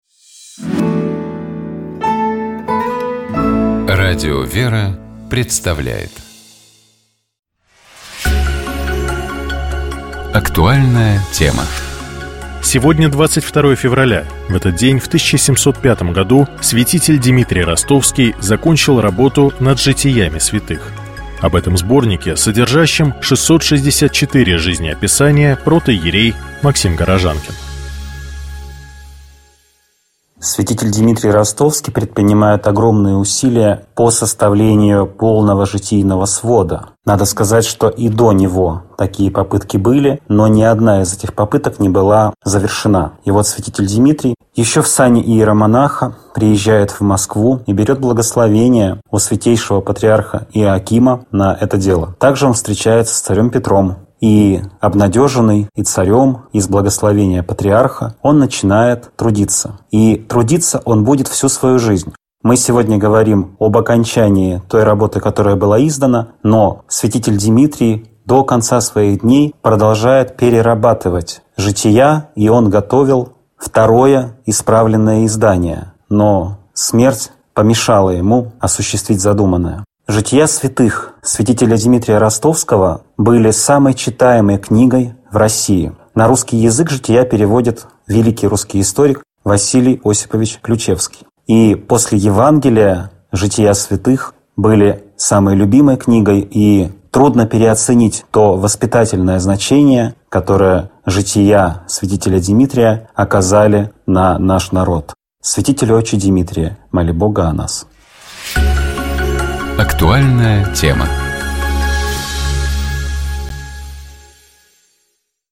Каждую пятницу ведущие, друзья и сотрудники радиостанции обсуждают темы, которые показались особенно интересными, важными или волнующими на прошедшей неделе.